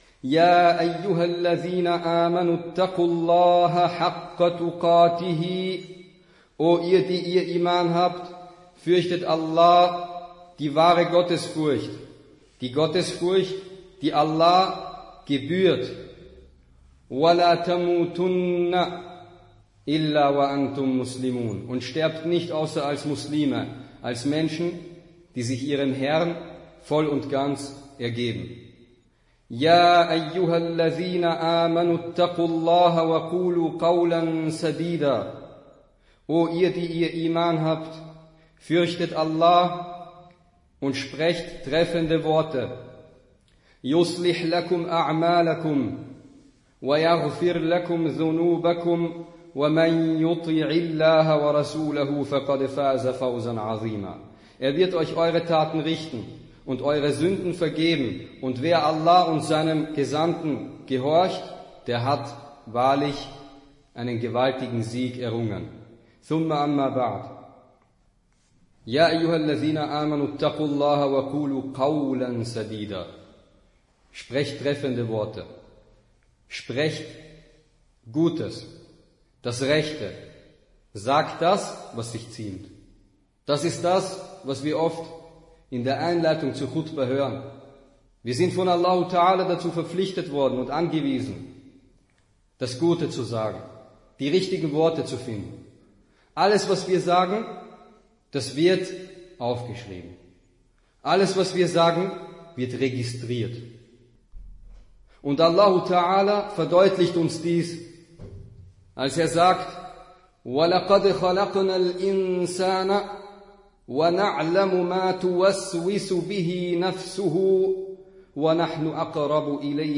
Freitagsansprache: Das viele Reden und Lachen
Die eigentliche Ansprache besteht aus zwei Teilen, dazwischen eine kurze Pause. Am Ende folgt das Gebet mit Rezitation von Versen aus dem Koran, welche meistens auch einen Bezug zum Thema haben oder in der Ansprache erwähnt werden. Die im deutschen Hauptteil der Ansprache zitierten arabischen Quelltexte unterbrechen den Redefluss nicht nennenswert und werden immer übersetzt.
Ursprünglicher Ort der Ansprache: al-Iman-Moschee in Wien